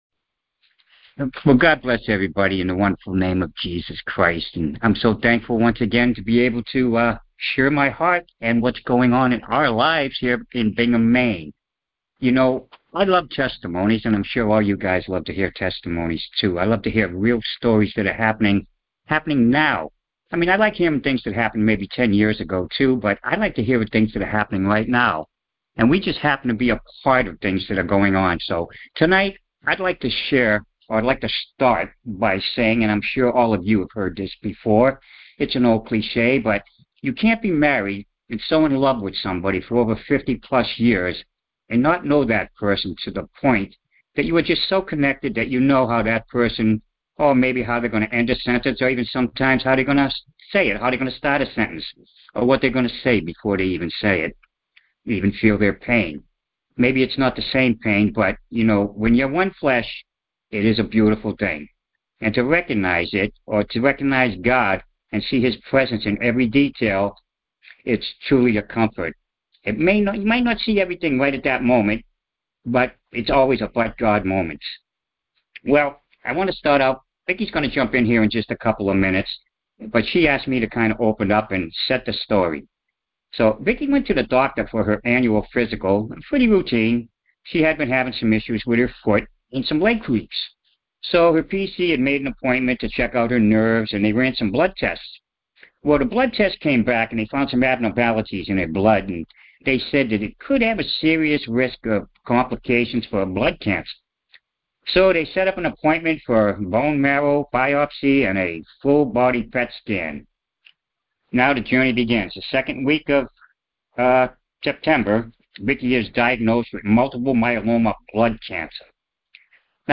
Simply Blessed Details Series: Conference Call Fellowship Date: Thursday, 09 October 2025 Hits: 229 Scripture: Ephesians 3:20 Play the sermon Download Audio ( 8.74 MB )